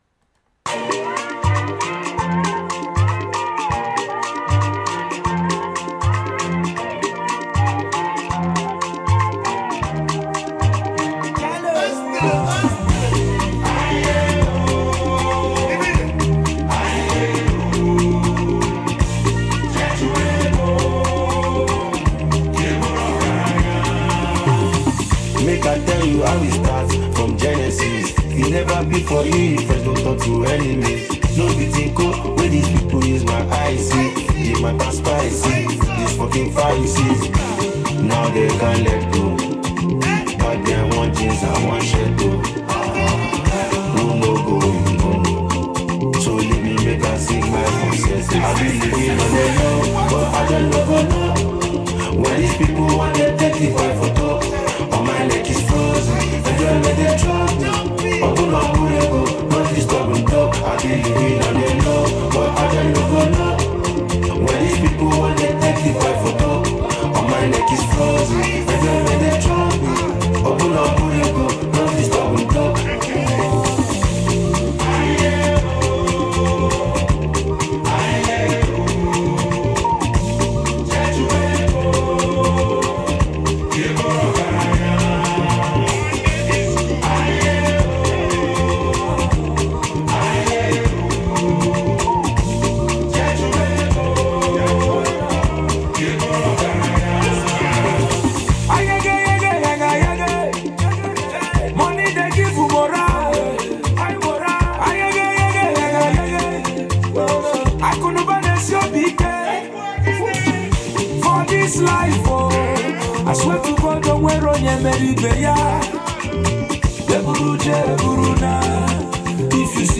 soulful new tune
smooth vocals
With rich Afrobeat rhythms and heartfelt lyrics